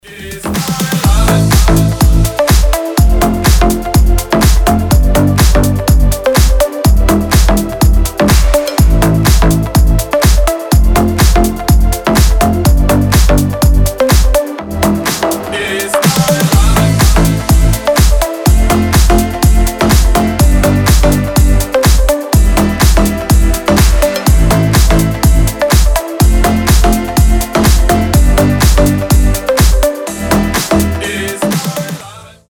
deep house
мелодичные
retromix
заводные
Club House
ремиксы
Цепляющая танцевальная мелодия получилась